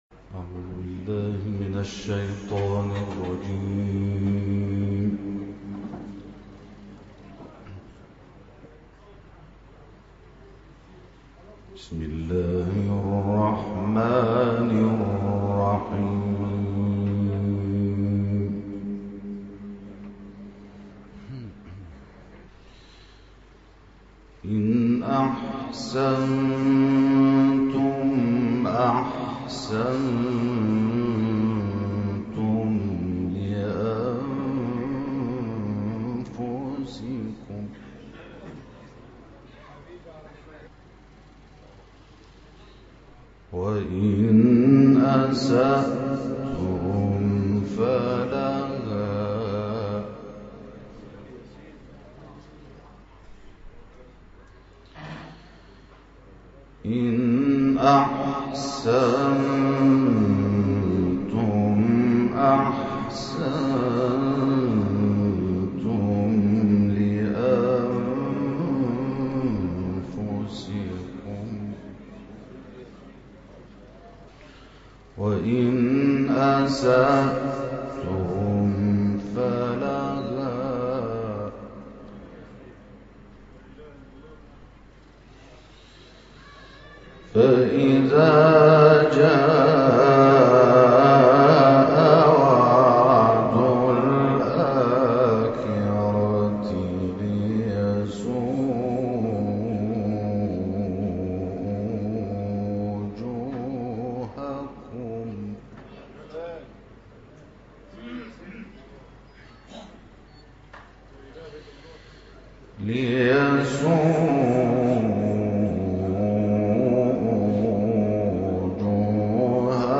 جدیدترین تلاوت «انور شحات» در مصر
این تلاوت 46 دقیقه‌ای طی هفته گذشته در کشور مصر اجرا شده که توسط یکی از سمیعه‌های انور شحات در اختیار گروه‌های تلگرامی قرار داده شده است. متاسفانه تصدیق پایانی این تلاوت ضبط نشده است.